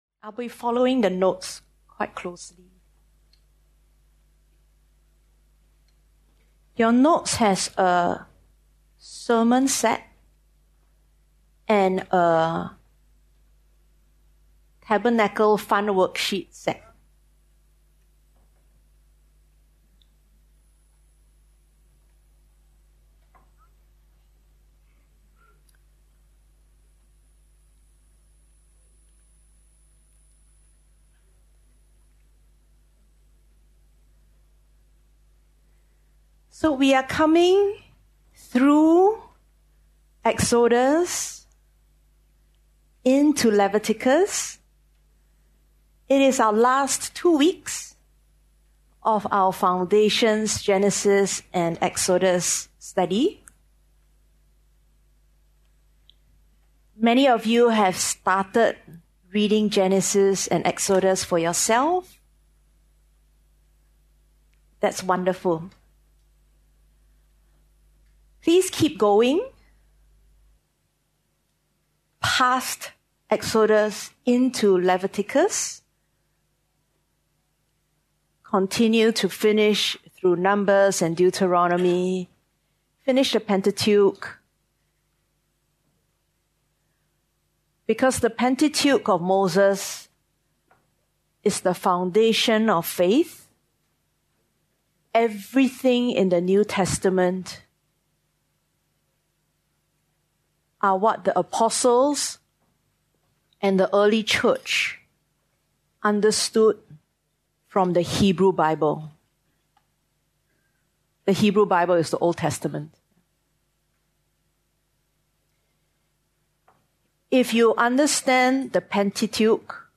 Otherwise just click, the video / audio sermon will just stream right to you without download.